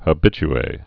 (hə-bĭch-ā, hə-bĭch-ā)